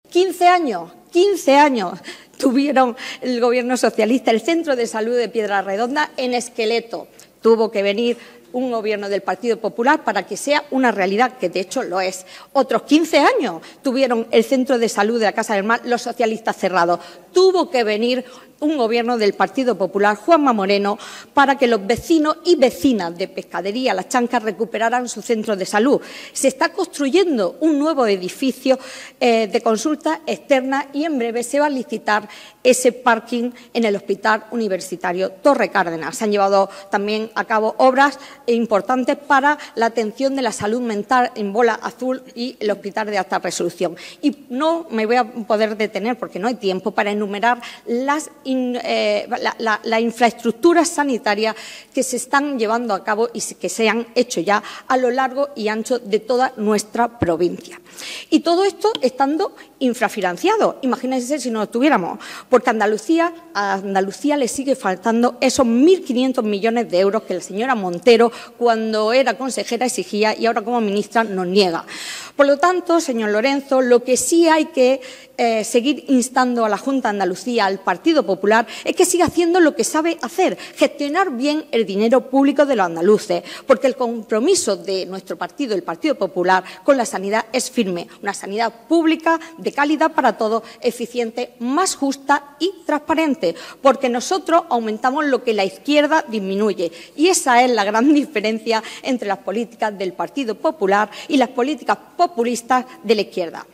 La portavoz del Grupo Municipal Popular y del Equipo de Gobierno en el Ayuntamiento de Almería, Sacramento Sánchez, ha destacado los esfuerzos realizados por el gobierno de la Junta de Andalucía, presidido por Juanma Moreno, para garantizar la calidad y accesibilidad de los servicios sanitarios en la ciudad, en el debate de la moción en defensa de la sanidad pública elevada al Pleno esta mañana.
CORTE-SACRAMENTO-SANCHEZ-SANIDAD-1.mp3